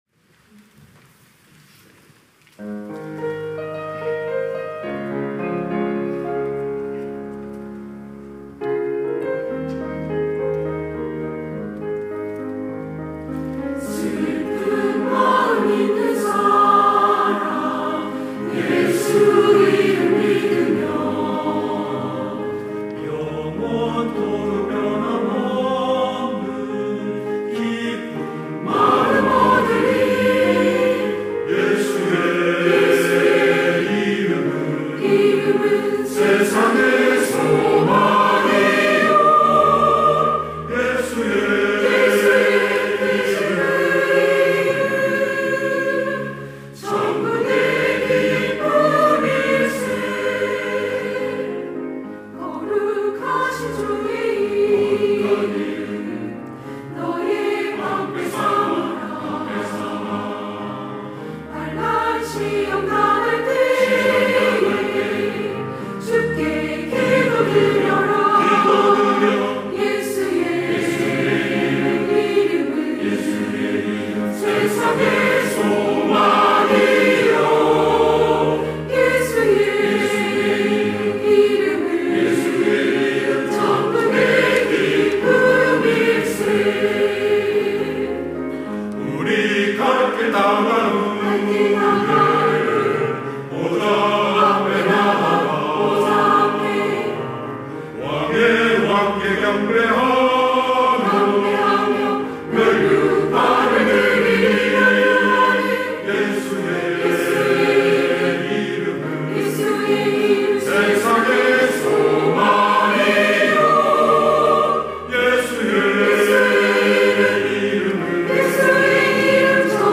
시온(주일1부) - 슬픈 마음 있는 사람
찬양대 시온